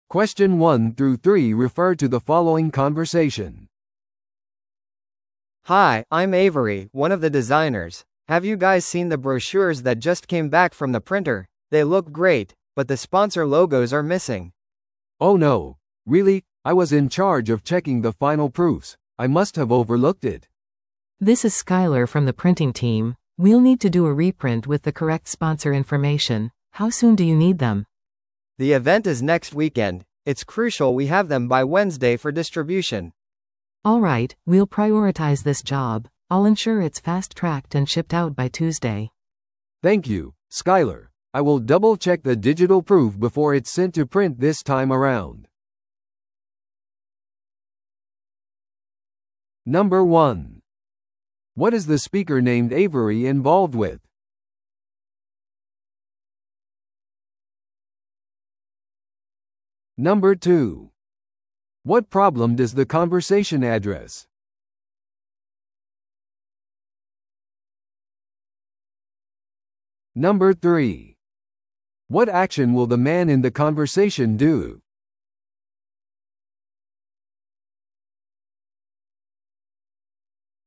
No.2. What problem does the conversation address?